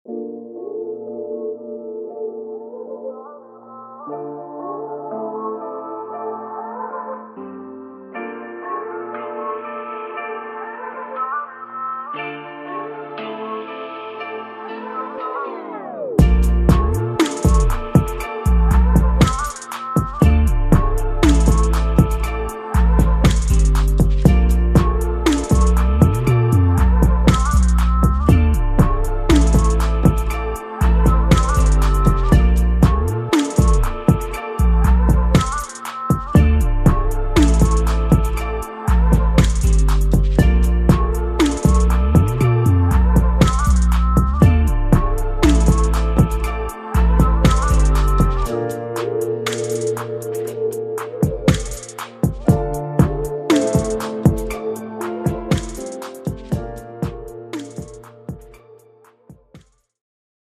Rnb
Smooth & pounding!
• Warm 808 & Bass samples layered with vocal chops